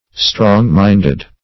Strong-minded \Strong"-mind`ed\, a.